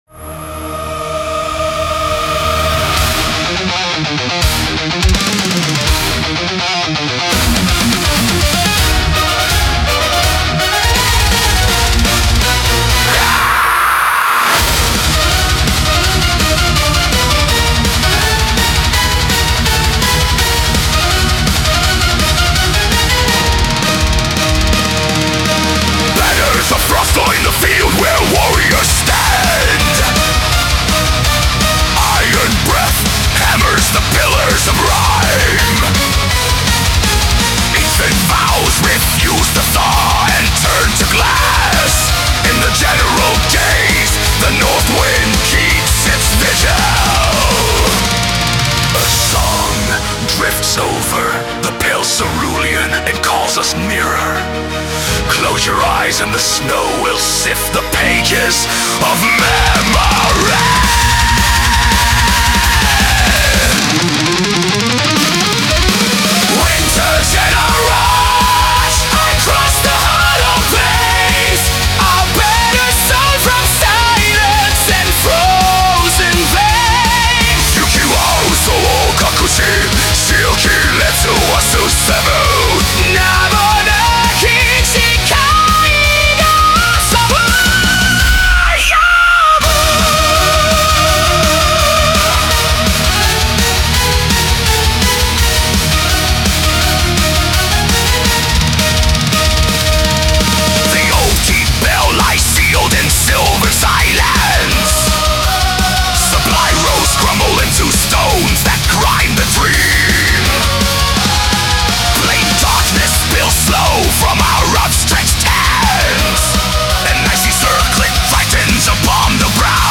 Symphonic Death Metal